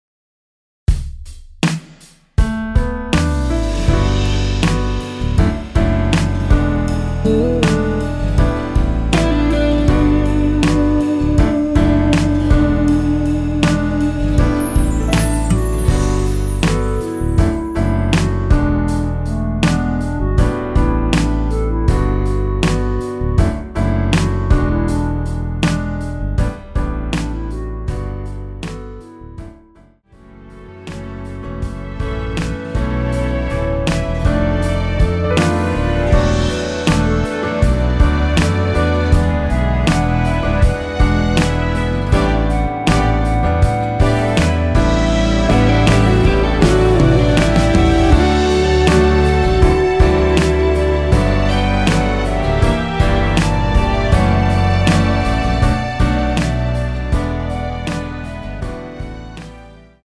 원키에서(-2)내린 멜로디 포함된 MR 입니다.(미리듣기 참조)
D
앞부분30초, 뒷부분30초씩 편집해서 올려 드리고 있습니다.
중간에 음이 끈어지고 다시 나오는 이유는